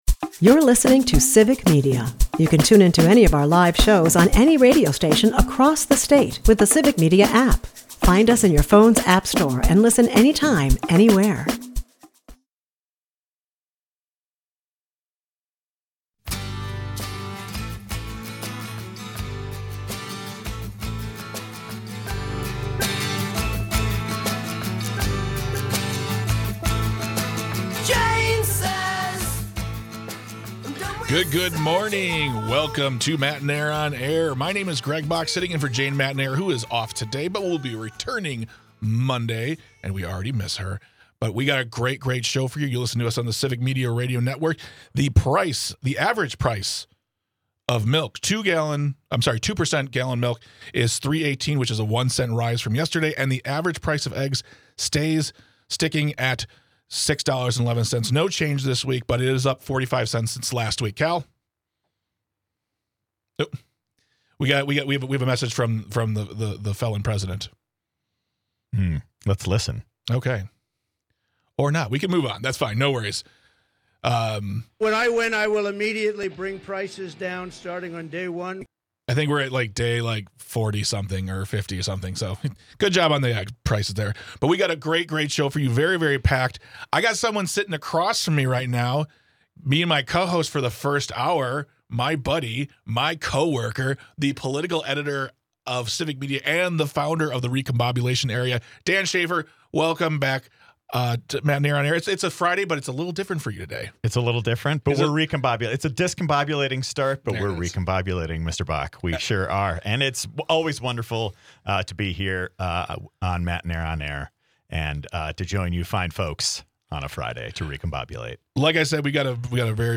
There is a frank discussion about using kids in political ads and we hear from you on the matter.
Matenaer On Air is a part of the Civic Media radio network and airs Monday through Friday from 10 am - noon across the state.